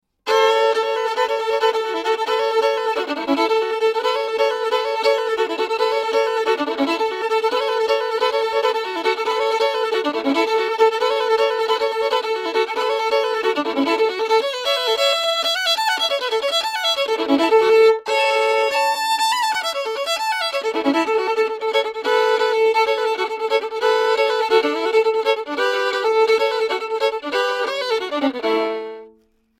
Žánr: Bluegrass.